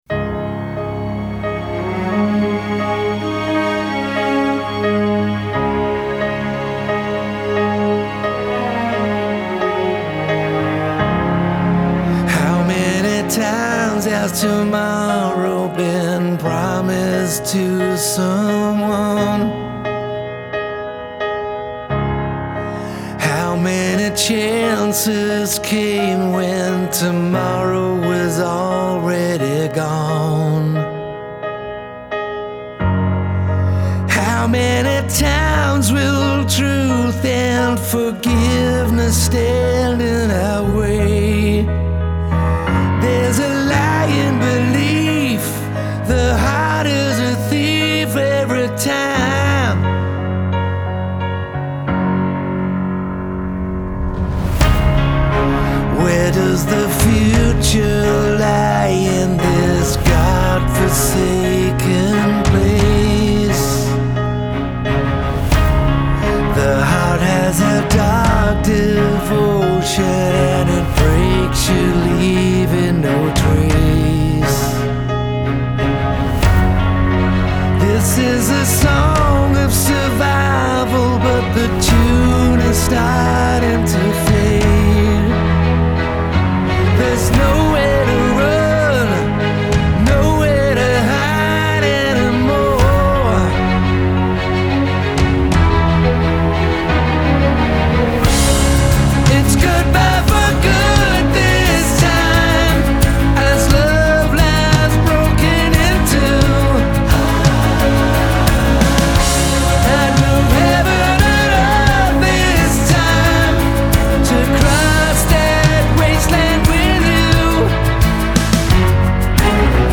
Genre : Rock, Pop